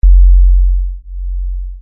MB Kick (26).wav